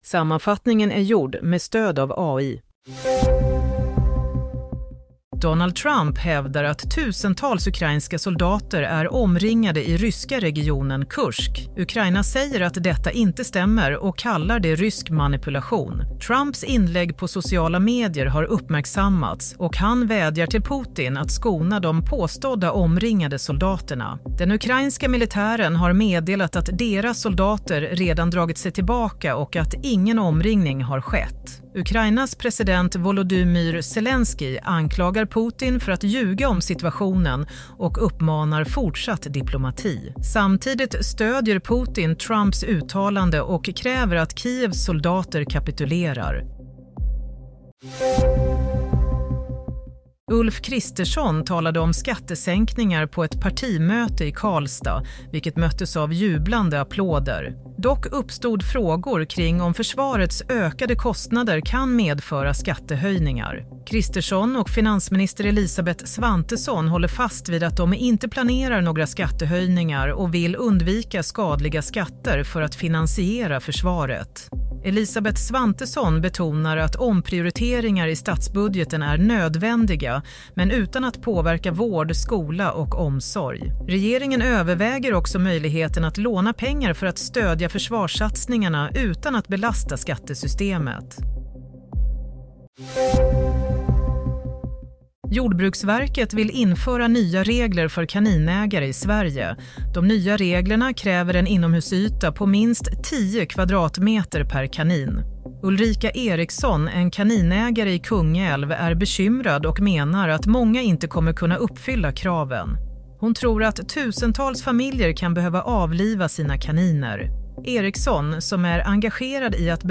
Nyhetssammanfattning - 14 mars 22:00